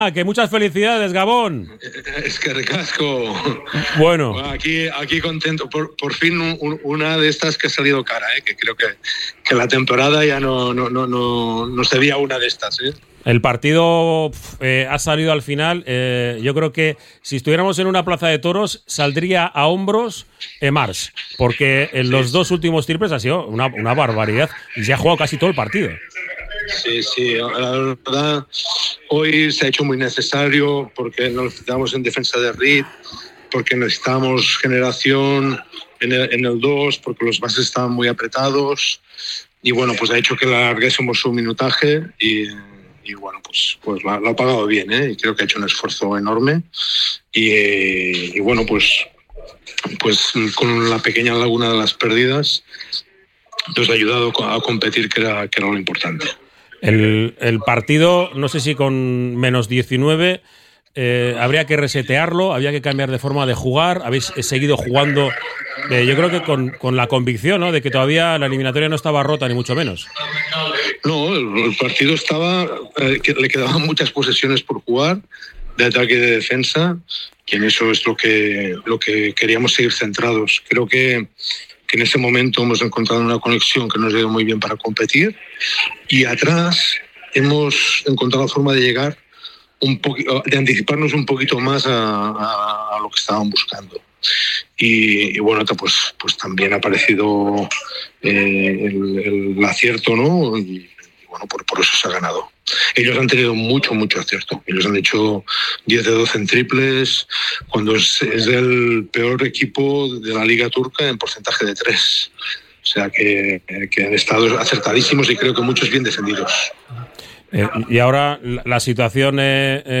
Jaume Ponsarnau, entrenador de los «hombres de negro», ha analizado la gesta de su equipo en una entrevista en la que ha destacado el esfuerzo de los jugadores y el impacto de Muhammad-Ali Abdur-Rahkman.